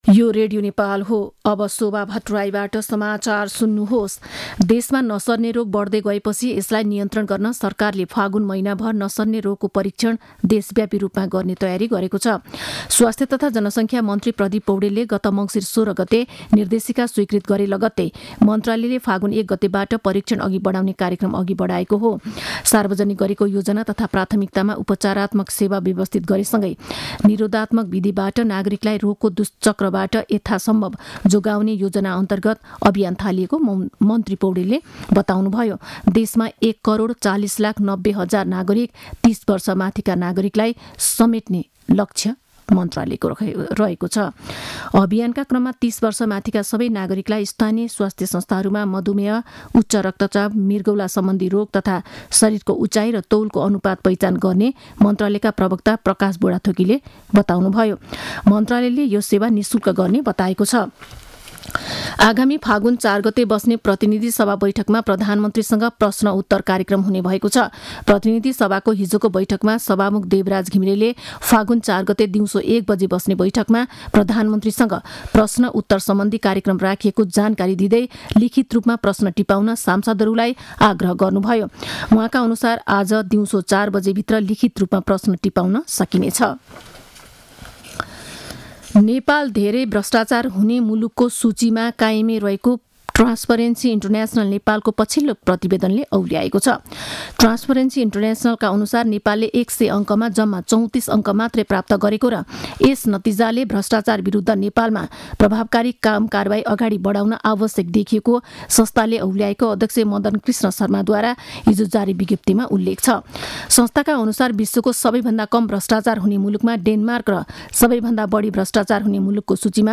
दिउँसो १ बजेको नेपाली समाचार : १ फागुन , २०८१
1-pm-news-1-5.mp3